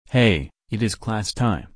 hey-it-is-class-time.mp3